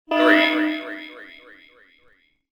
SelfDestructThree.wav